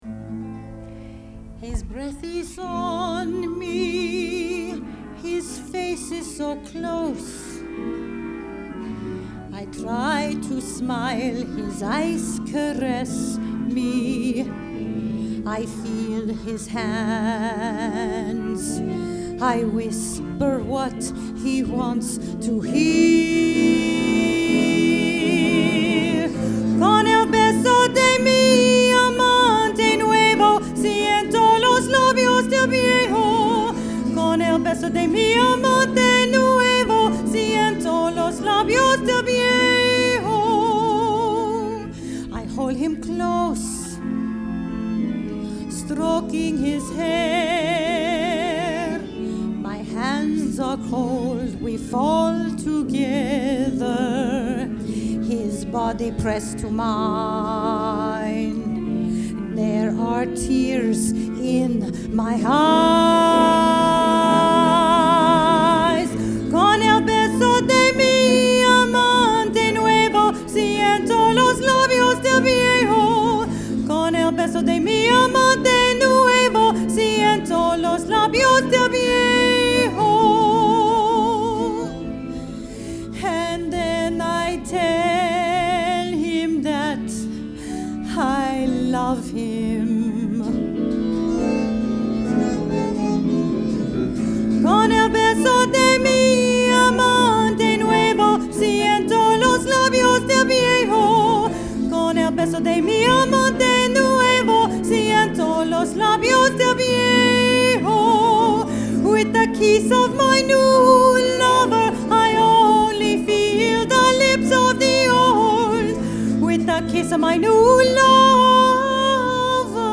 A new play with music